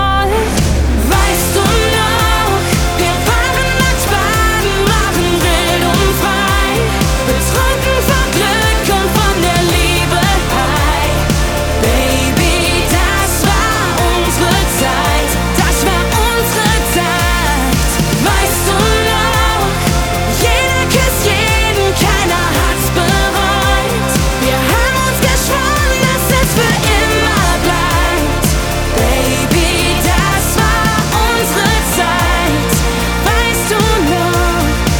Жанр: Поп
# German Pop